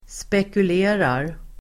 Uttal: [spekul'e:rar]